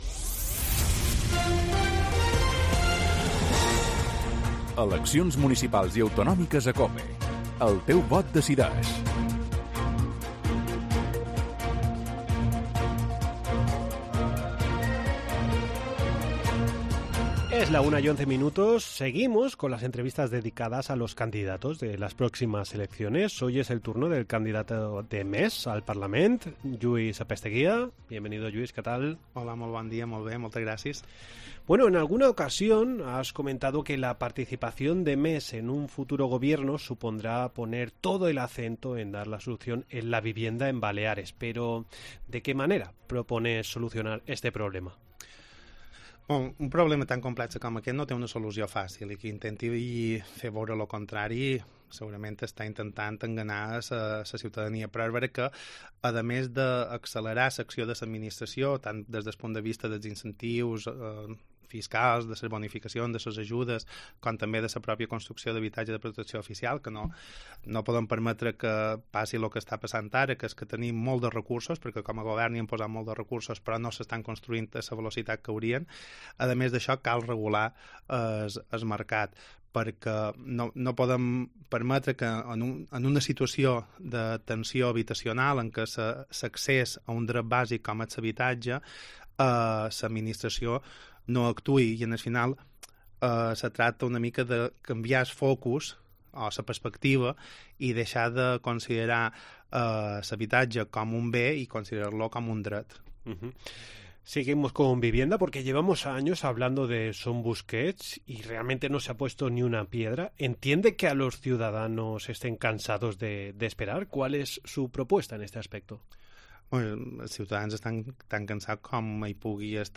Seguimos con las entrevistas dedicadas a los candidatos de las próximas elecciones, hoy es el turno del candidato de MES al Parlament, Lluís Apesteguia: